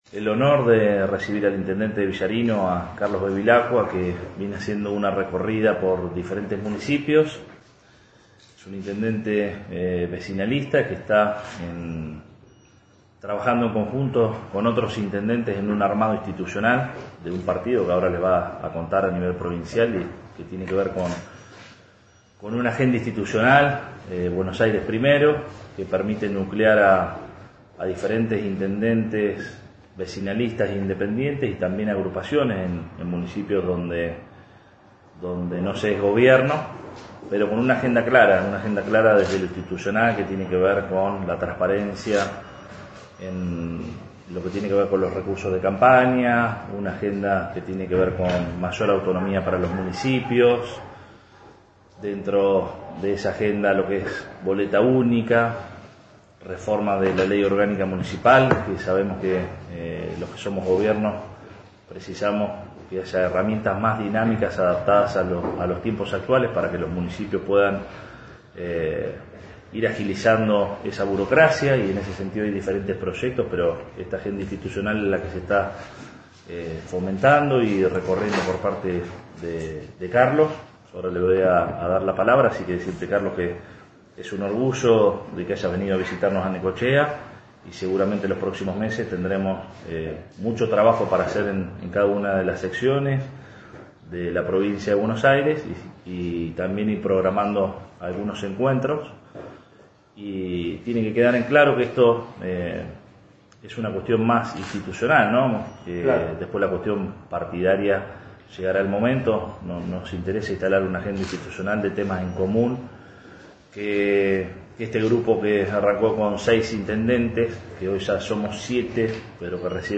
Terminada la entrevista, ambos funcionarios ofrecieron una conferencia de prensa en la que resaltaron la importancia de proponer un cambio en las estructuras institucionales que permitan a los municipios tomar el protagonismo que merecen a la hora de tomar decisiones en beneficio de sus vecinos.